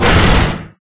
Close3.ogg